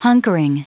Prononciation du mot hunkering en anglais (fichier audio)
Prononciation du mot : hunkering